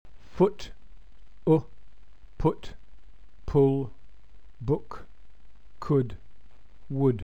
Lax vowels are always short